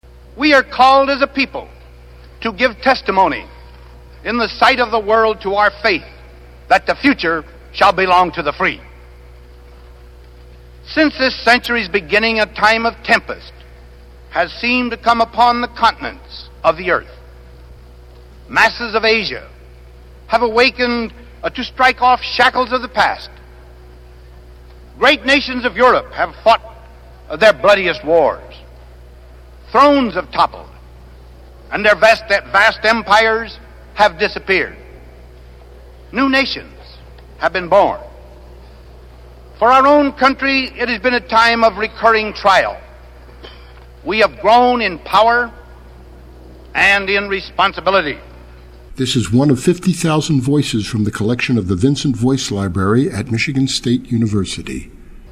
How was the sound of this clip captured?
From his 1953 inaugural address. (0:55)